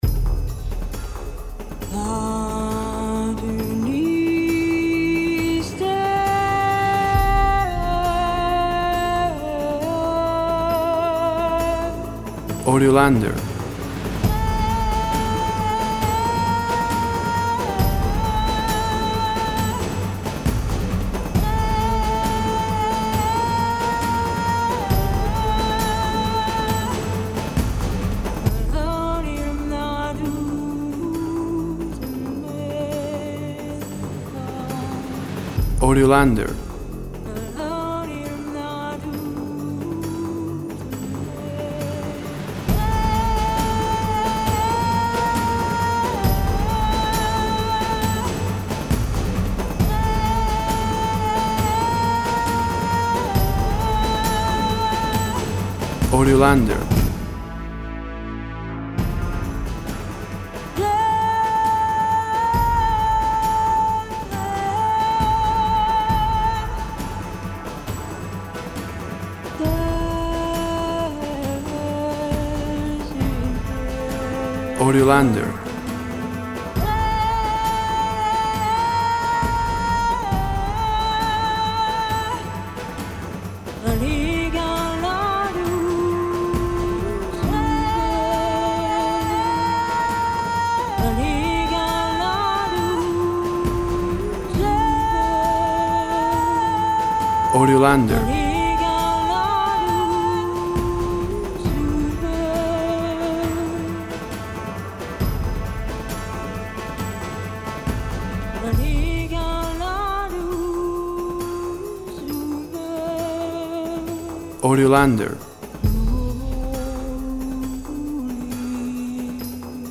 Tempo (BPM) 127